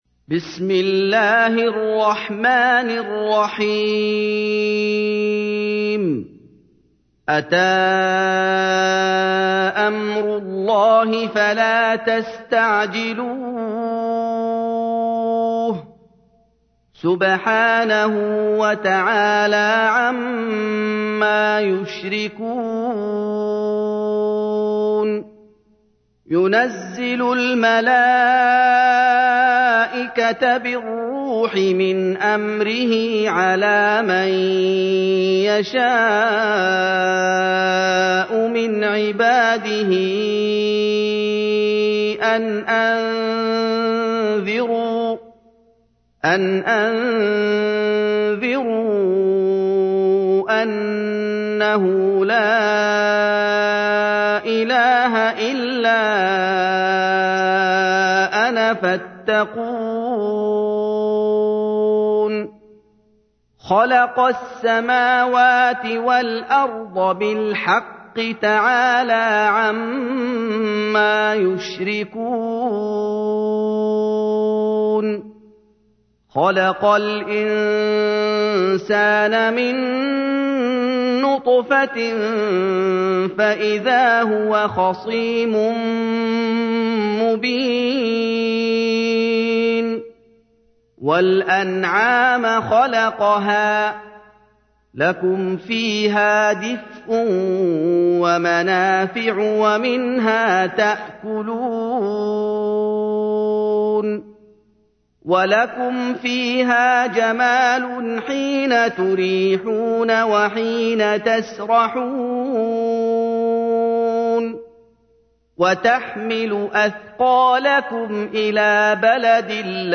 تحميل : 16. سورة النحل / القارئ محمد أيوب / القرآن الكريم / موقع يا حسين